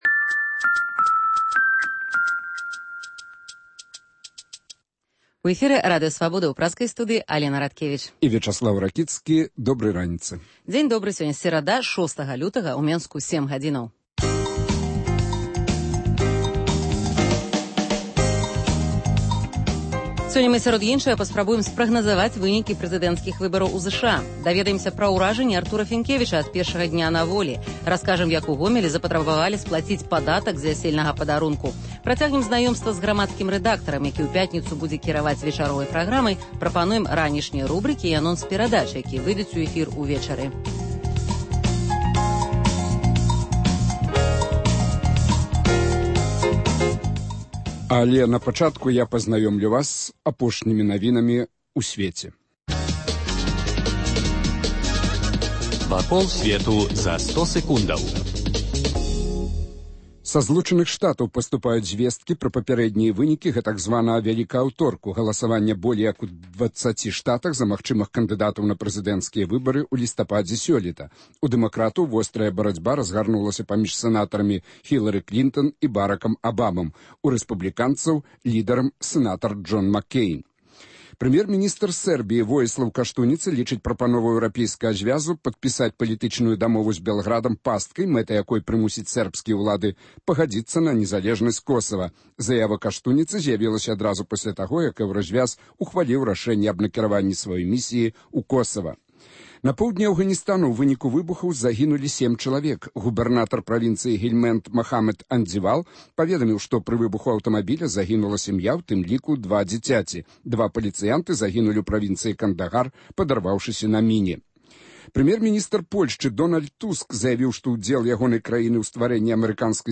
Ранішні жывы эфір